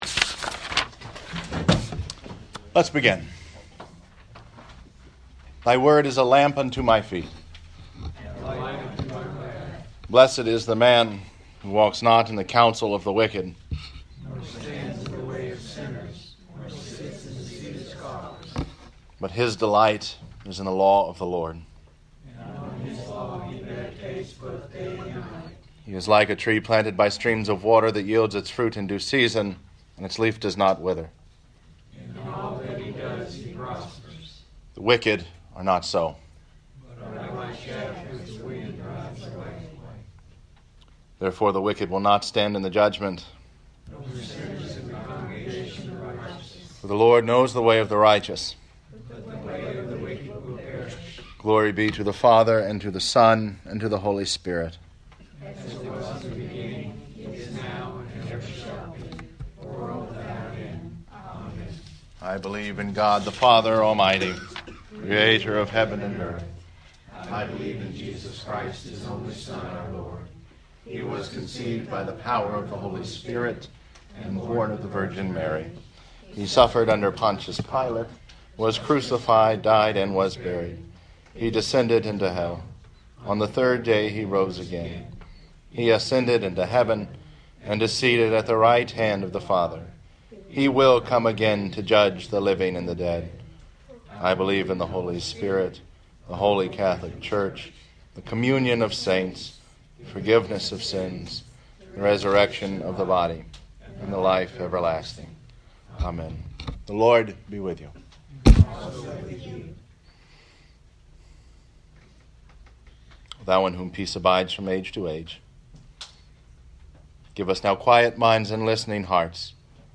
Bible Study
Bible-Study-Revelation-2.mp3